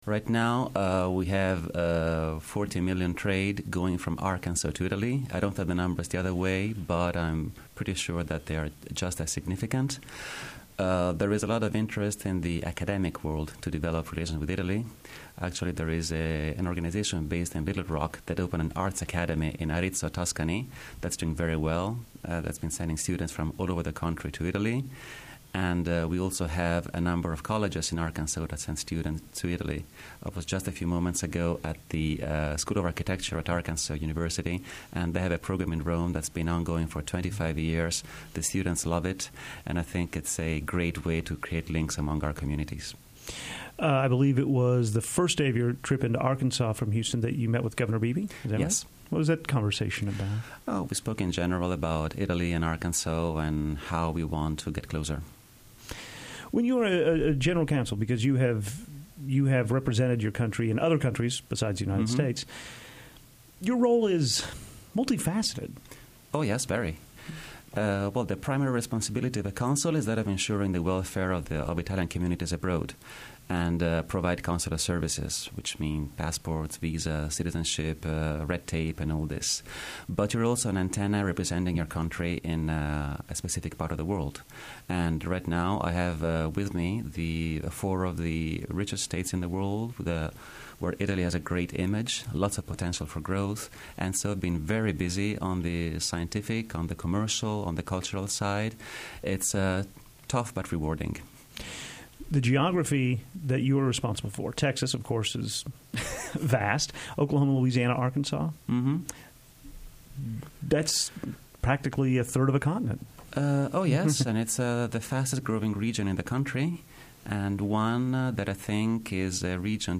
Italian Consul General Hon. Fabrizio Nava visited KUAF’s Anthony and Susan Hui News Studio to discuss cooperation efforts between the state of Arkansas and Italy. Nava is in charge of cooperation efforts in Texas, Arkansas, Oklahoma and Louisiana.